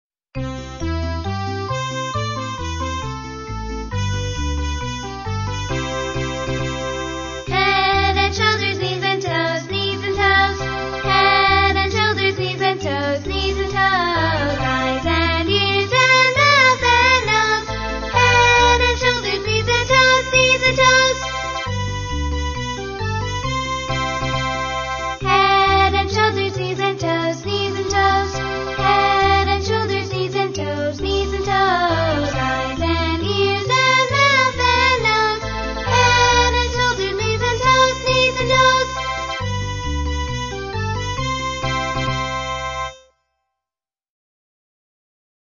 在线英语听力室英语儿歌274首 第57期:Head and Shoulders的听力文件下载,收录了274首发音地道纯正，音乐节奏活泼动人的英文儿歌，从小培养对英语的爱好，为以后萌娃学习更多的英语知识，打下坚实的基础。